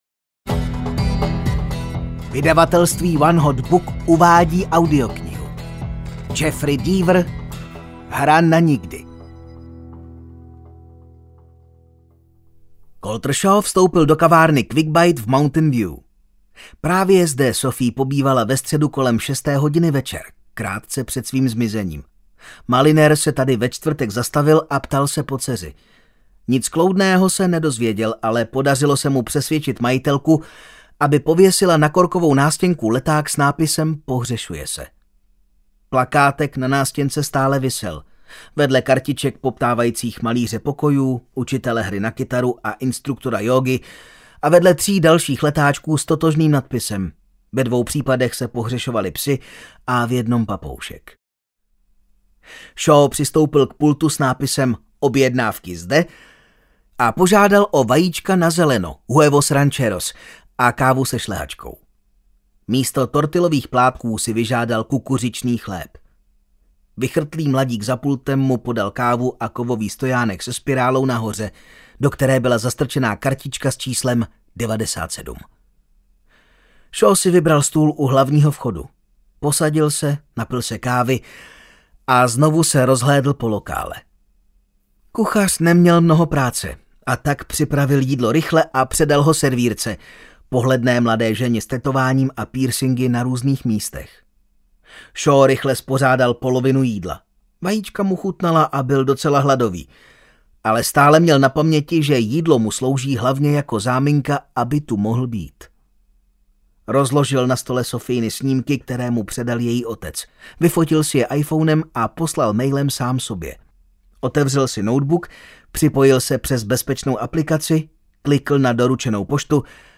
Hra na nikdy audiokniha
Ukázka z knihy